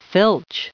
1429_filch.ogg